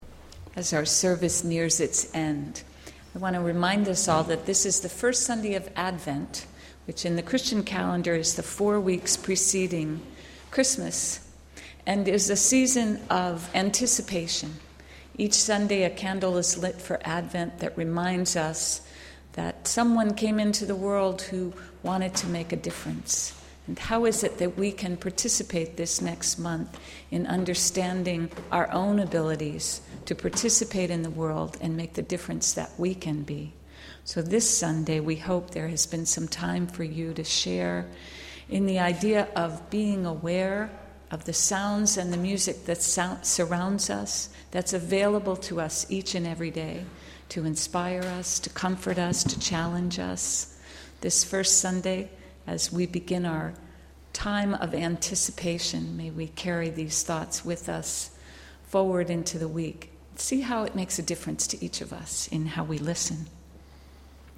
A mostly musical celebration of song and spirit jointly led by a group of lay leaders, singers